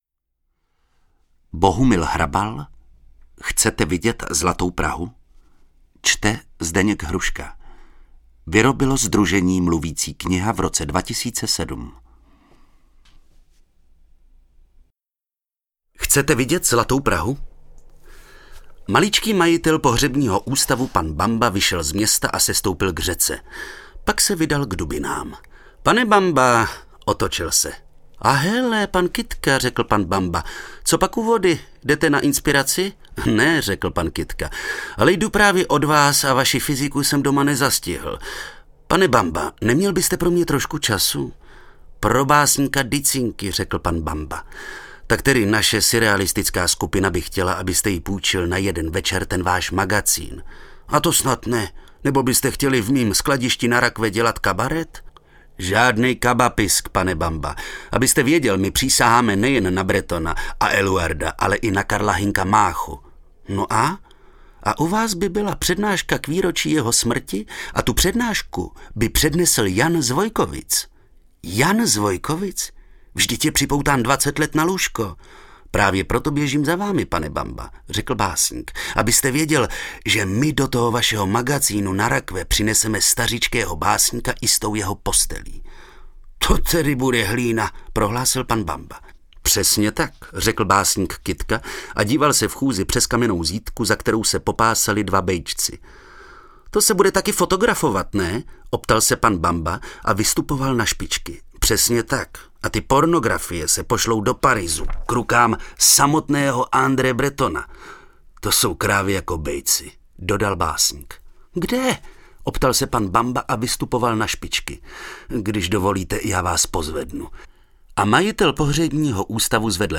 Mluvící kniha z.s.